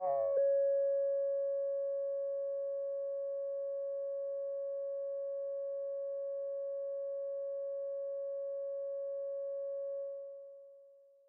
Roland Jupiter 4 PWM Strings " Roland Jupiter 4 PWM Strings C6 (PWM Strings85127 D561)
描述：通过Modular Sample从模拟合成器采样的单音。
Tag: CSharp6 MIDI音符-85 罗兰木星-4 合成器 单票据 多重采样